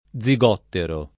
zigottero [ +z i g0 ttero ]